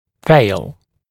[feɪl][фэйл]потерпеть неудачу; не исполнить, не сделать